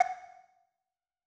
UI Sounds